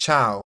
Ääntäminen
IPA : /ˈbaɪ/